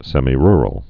(sĕmē-rrəl, sĕmī-)